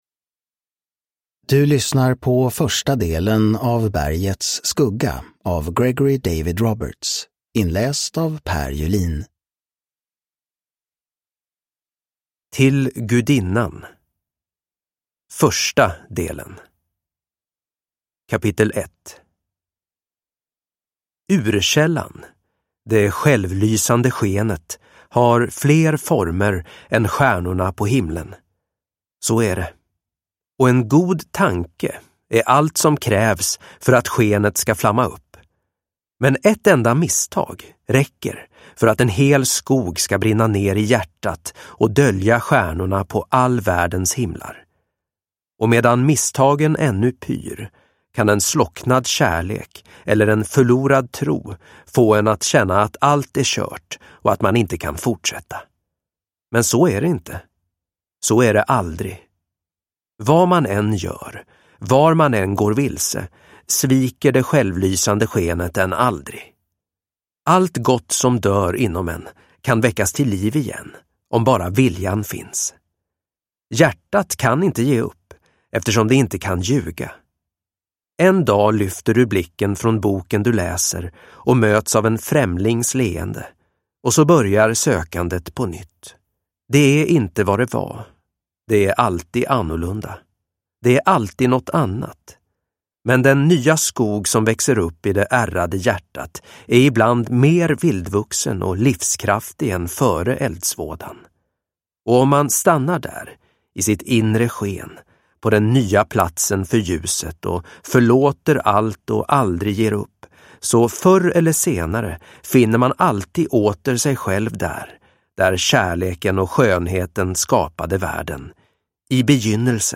Bergets skugga. Del 1 – Ljudbok – Laddas ner